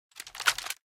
reload_end.ogg